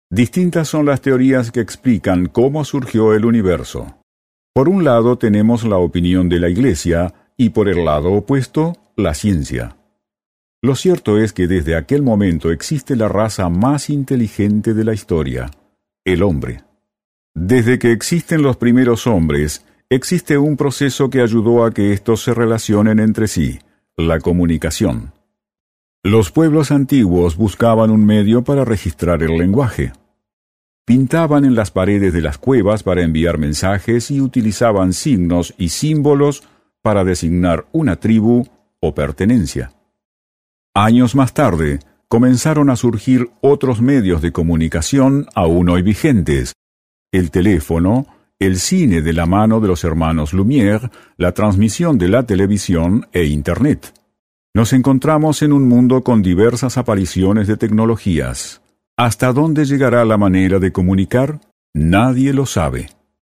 Sprecher spanisch (Südamerika). Dicción clara, firme, segura.
Sprechprobe: Sonstiges (Muttersprache):